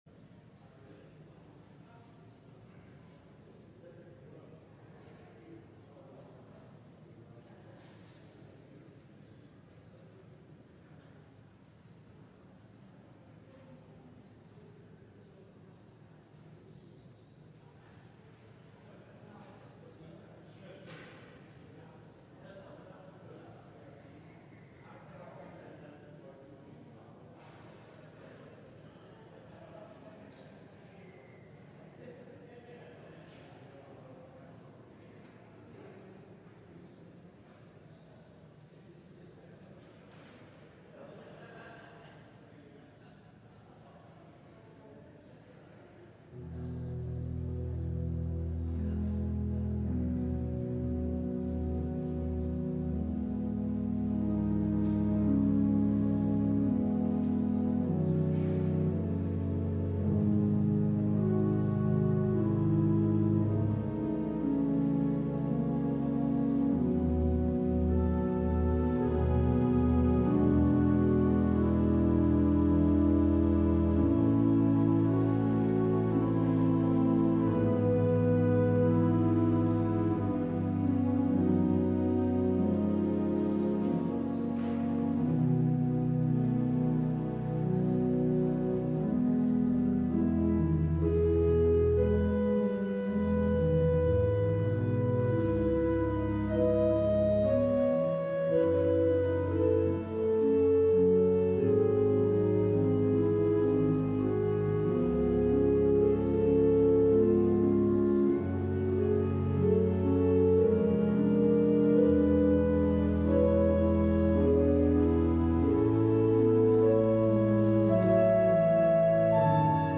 LIVE Evening Worship Service - The Hardest Parable